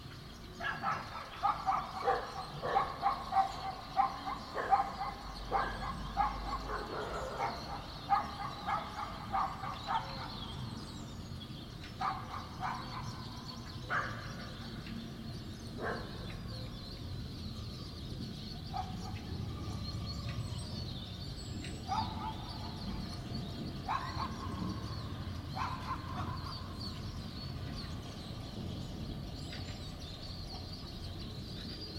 高级音响效果 自然界的鸟和狗 1
描述：自然、鸟类和狗的FX音景。用H2next录制的。